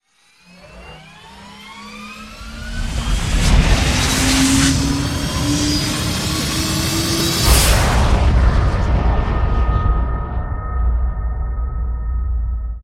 launch7.ogg